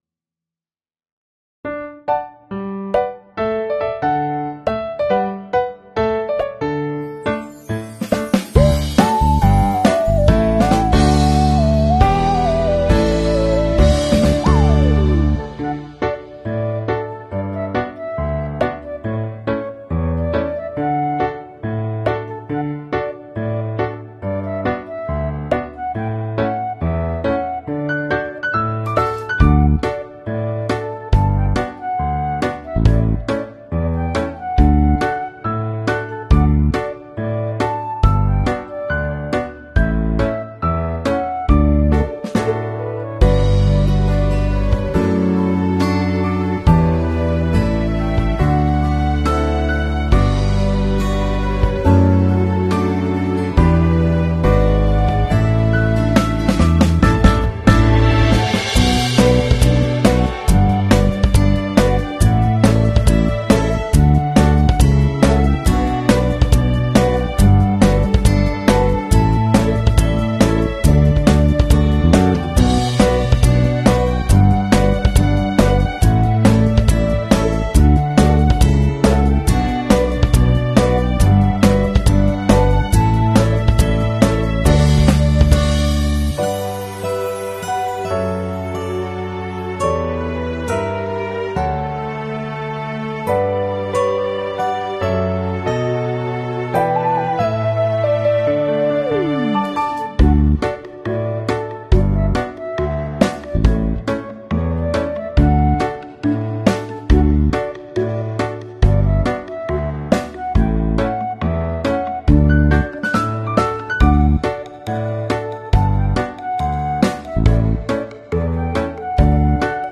Cute bunny sound effects free download